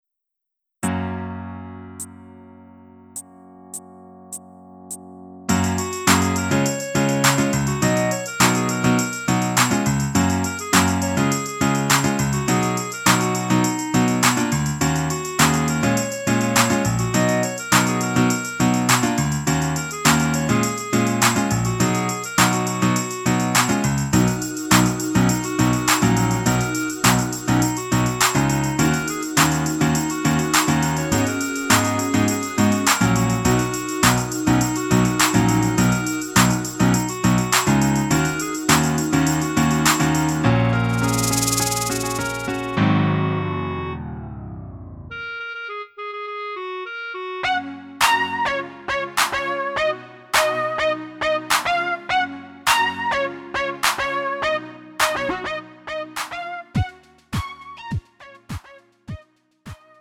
음정 -1키 3:14
장르 구분 Lite MR